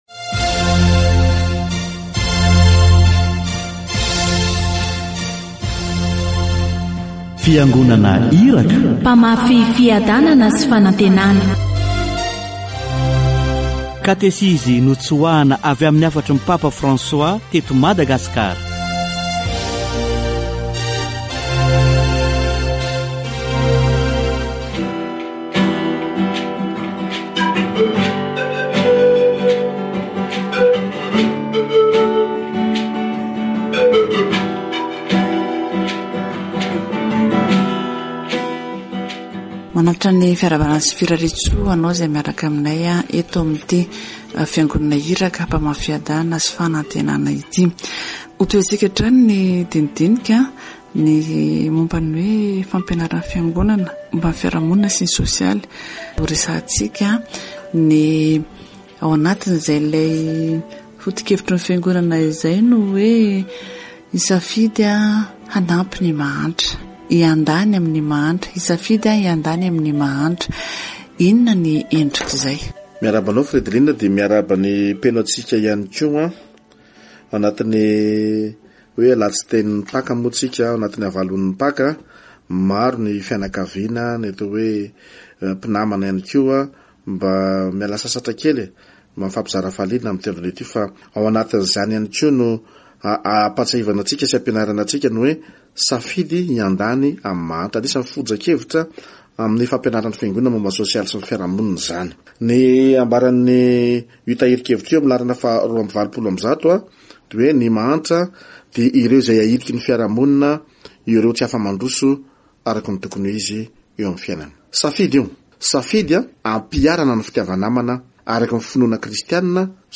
Catéchèse sur la pastorale sociale